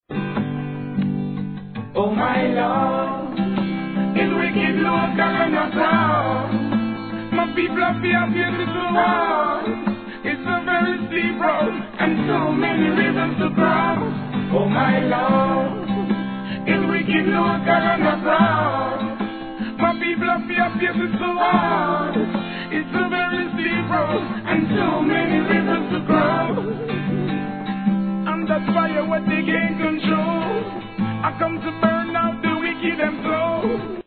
REGGAE
アコースティックONLYで奏でたHIT RIDDIM!!